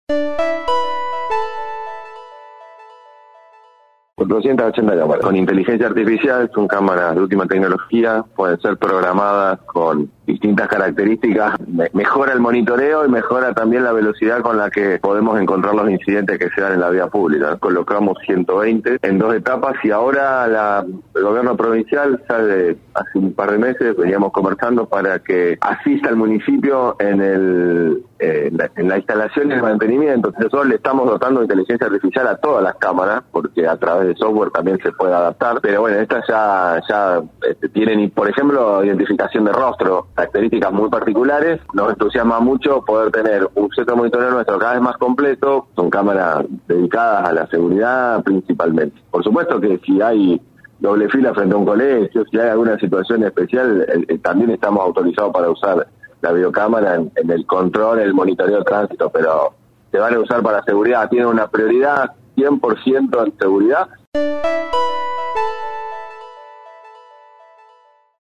El secretario de gobierno de la Municipalidad de Rosario, Sebastián Chale, dijo en contacto con LT3 que son 480 equipos que se instalarán en el corto plazo